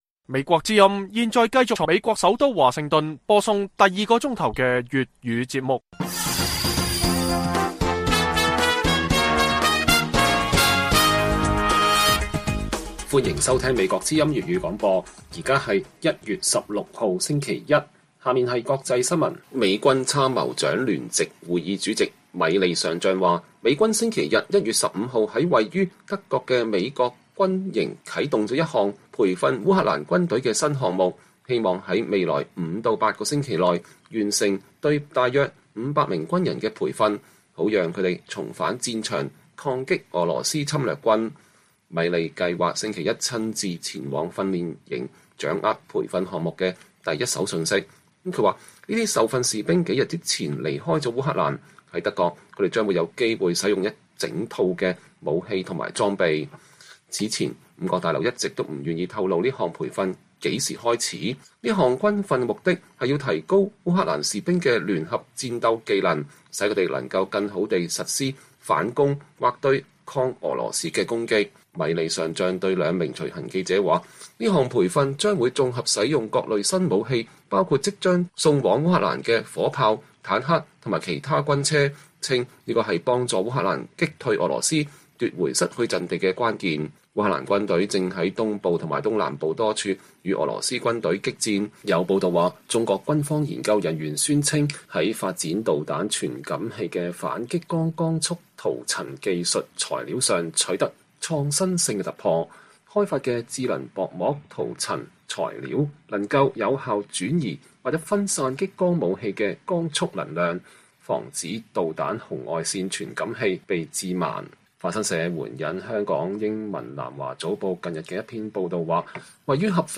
粵語新聞 晚上10-11點: 美軍培訓烏克蘭軍隊擊退俄軍入侵